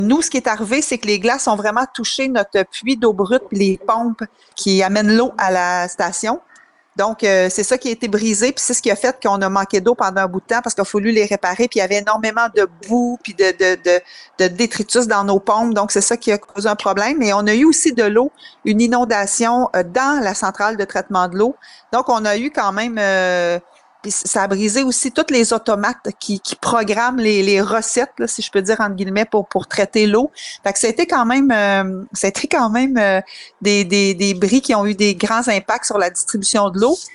Mme Dubois est revenue sur ce qui s’était passé il y a quelques mois.